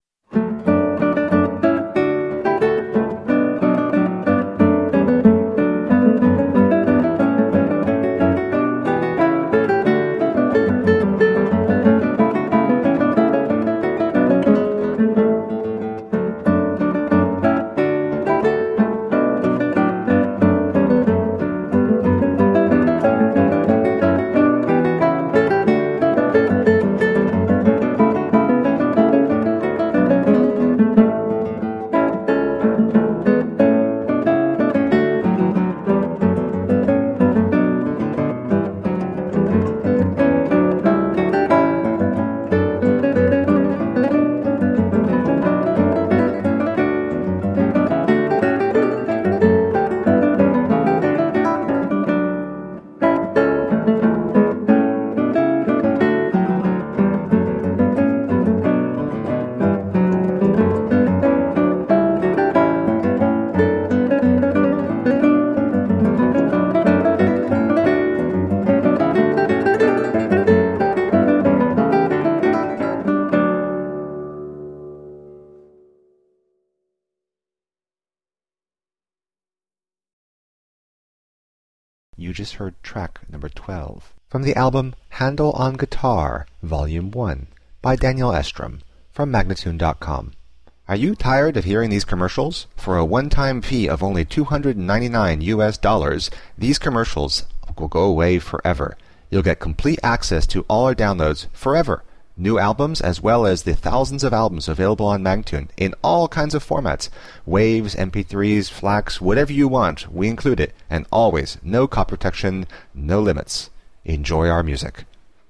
Colorful classical guitar.
Classical, Baroque, Instrumental
Classical Guitar